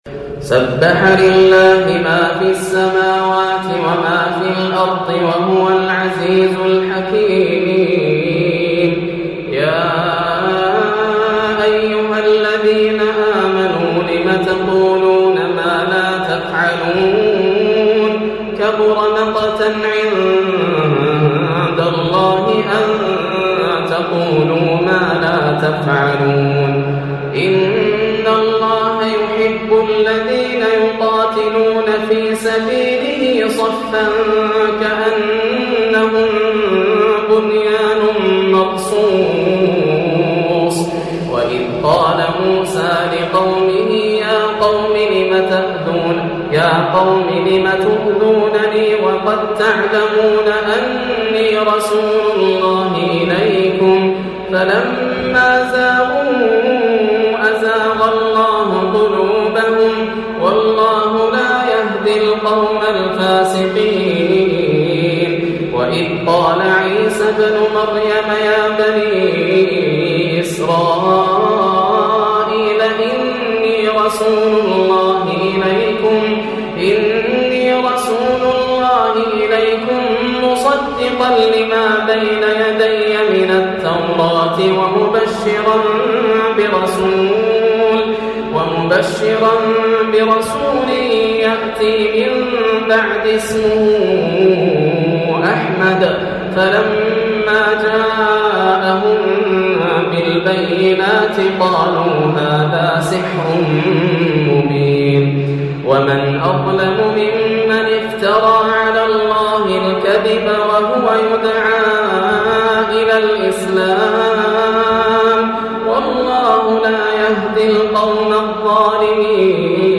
سورة الصف > السور المكتملة > رمضان 1430هـ > التراويح - تلاوات ياسر الدوسري